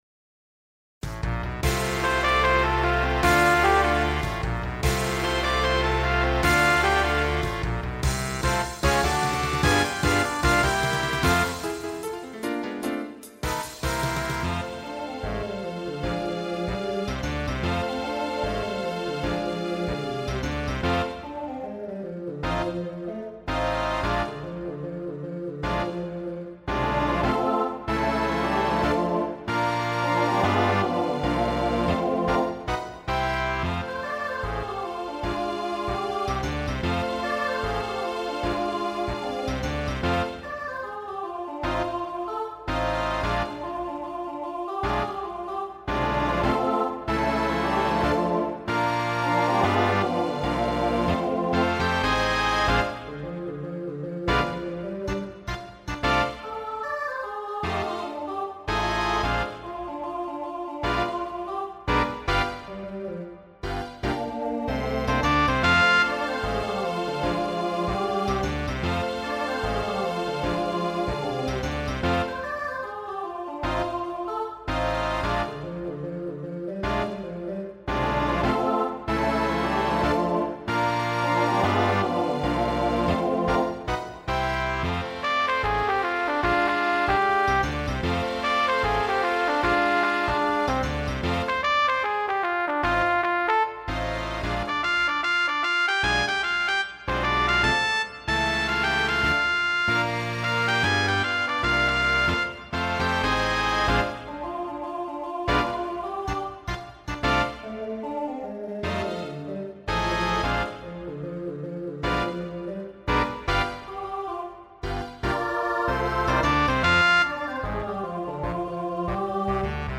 Voicing SATB Instrumental combo Genre Rock
Mid-tempo